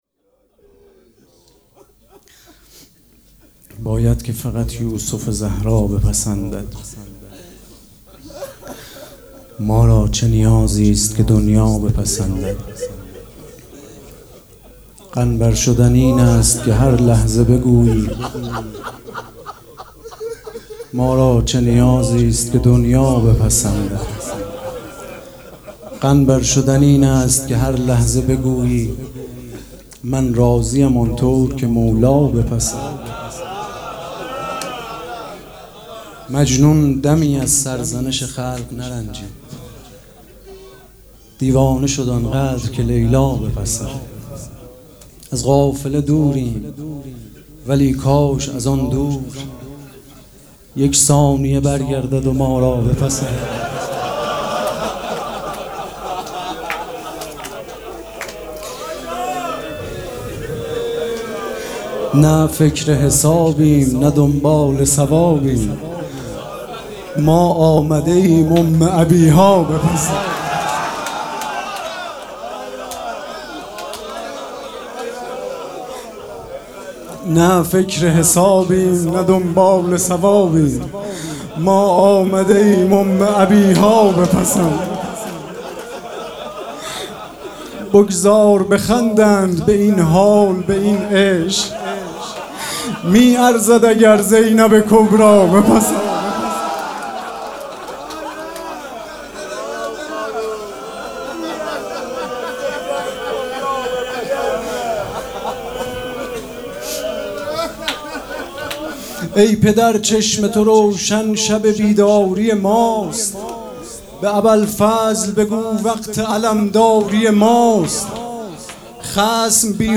مراسم عزاداری شب شهادت حضرت رقیه سلام الله علیها
شعر خوانی